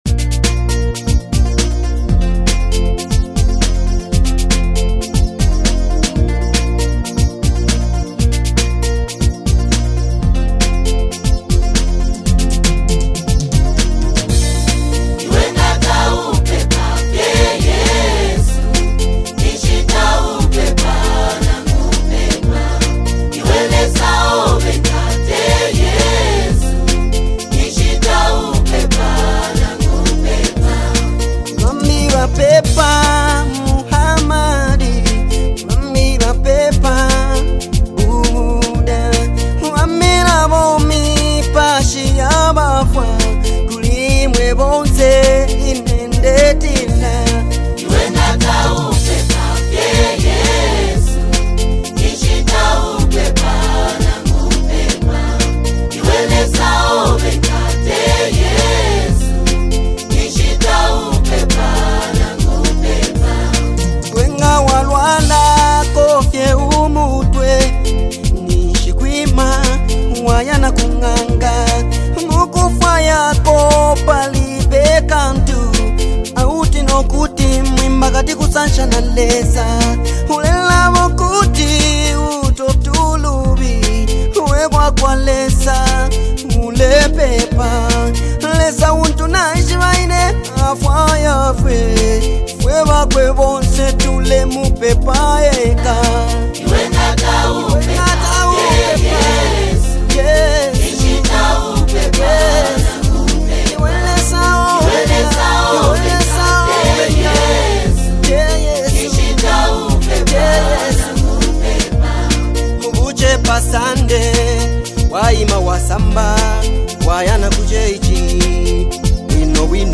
uplifting rhythms